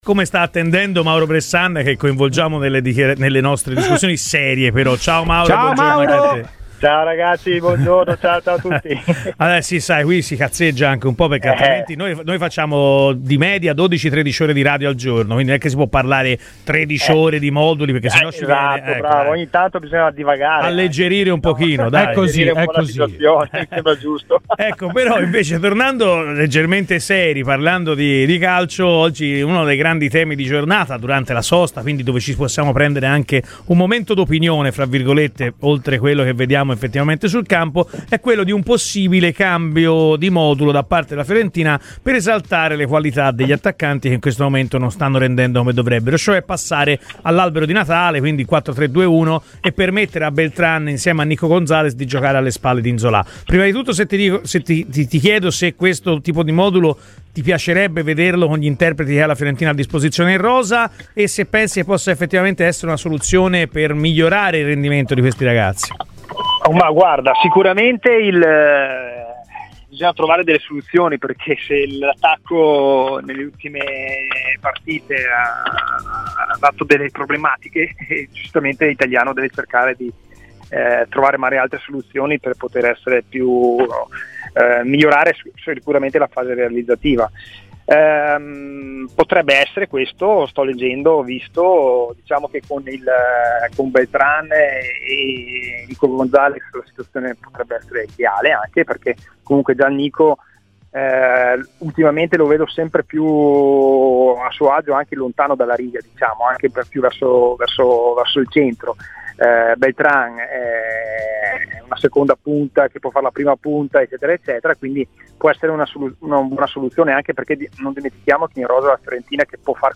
L’ex viola Mauro Bressan è intervenuto ai microfoni di Radio FirenzeViola, durante “Chi si compra?”, iniziando dal possibile cambio di modulo: “Bisogna trovare delle soluzioni perché al momento l’attacco sta dando delle problematiche.